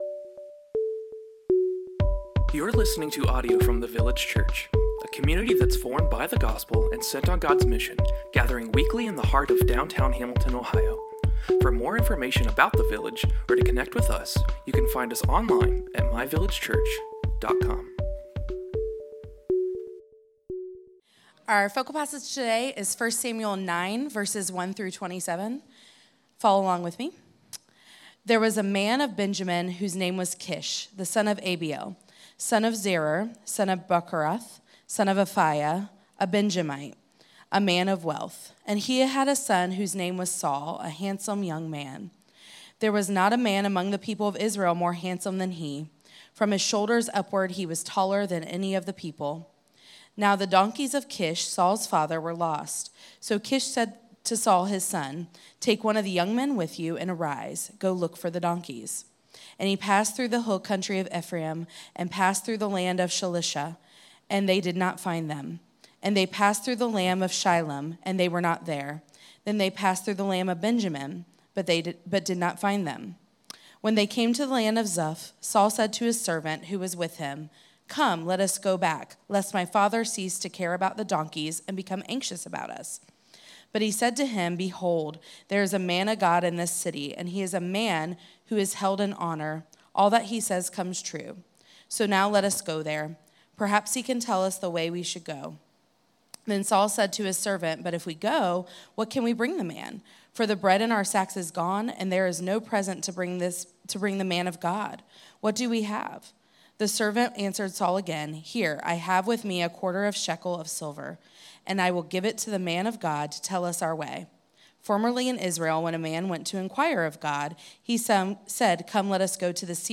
A sermon in our series covering the second-half of Exodus.